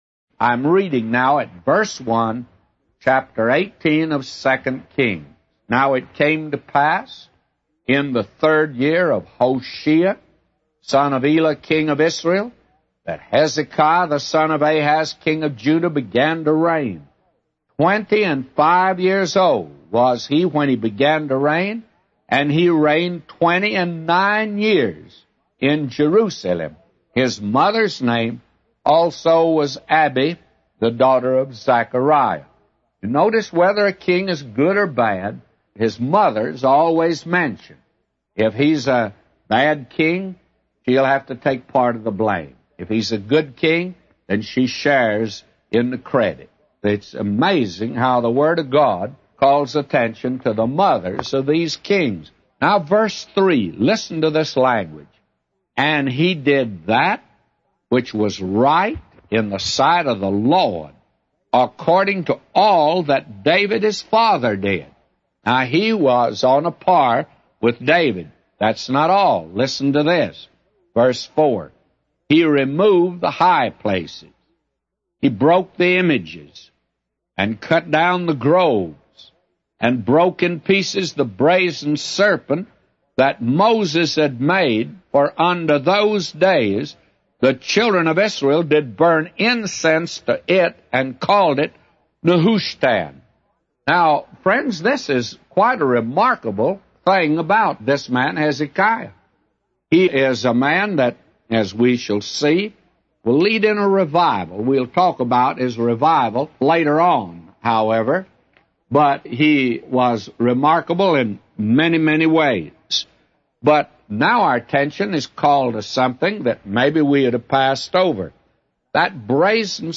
A Commentary By J Vernon MCgee For 2 Kings 18:1-999